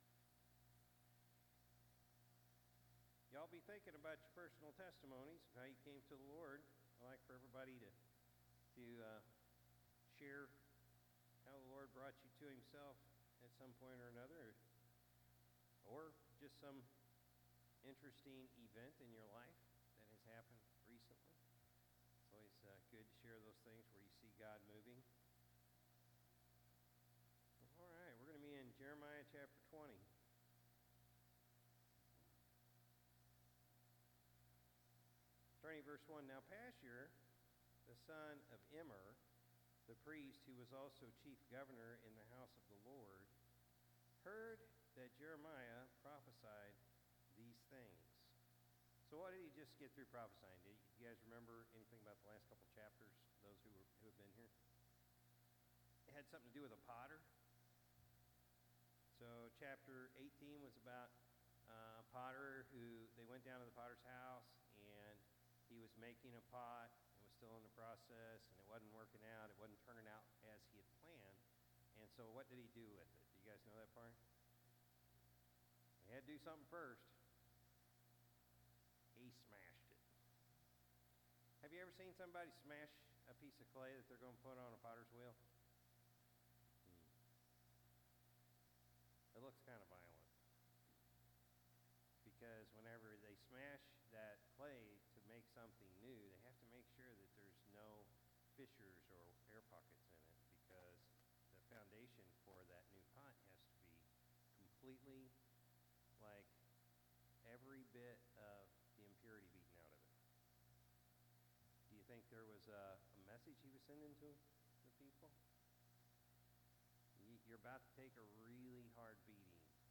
September-1-2024-Evening-Service.mp3